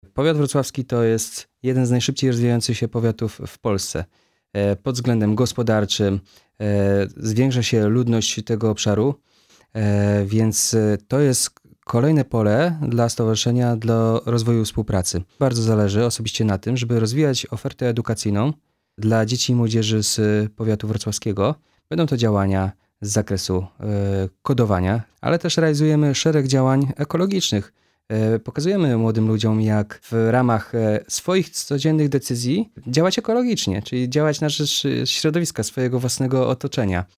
W studiu Radia Rodzina Włodzimierz Chlebosz